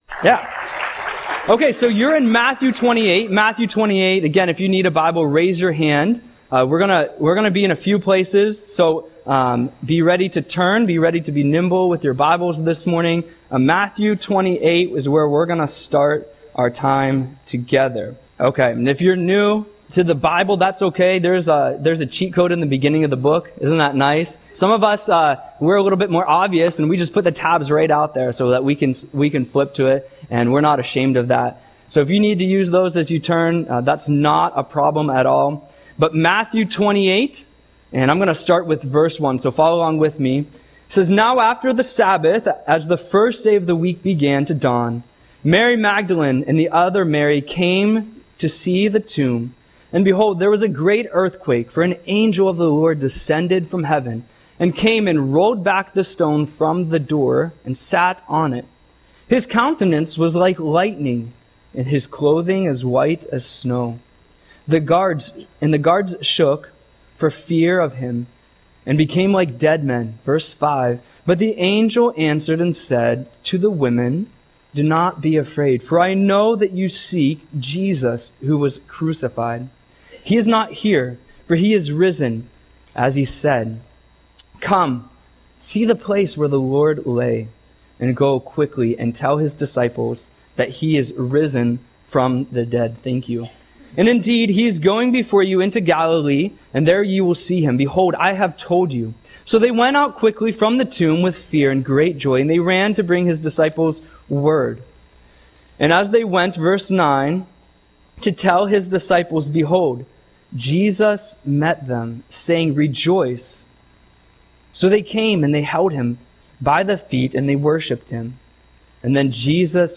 Audio Sermon - April 20, 2025